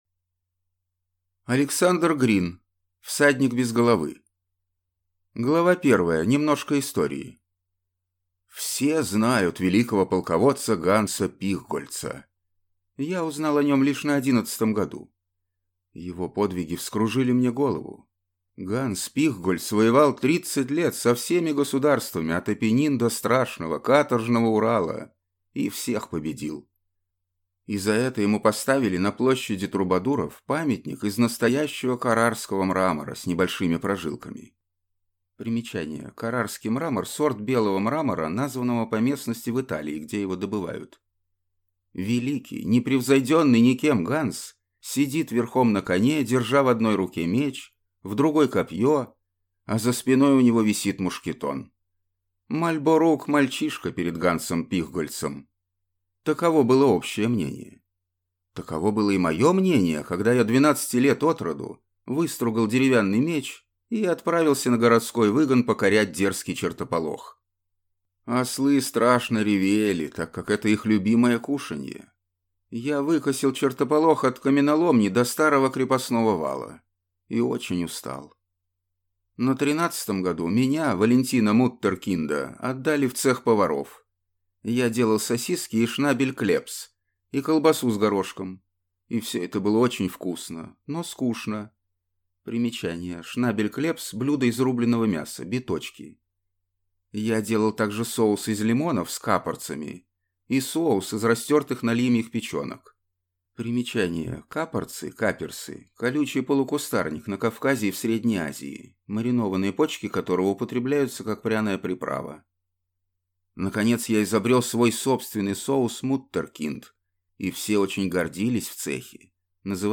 Аудиокнига Всадник без головы | Библиотека аудиокниг